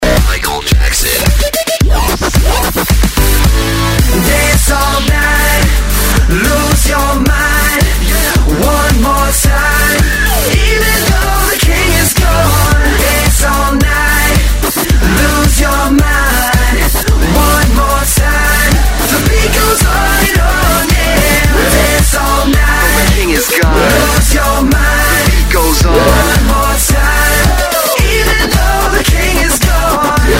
Dubstep рингтоны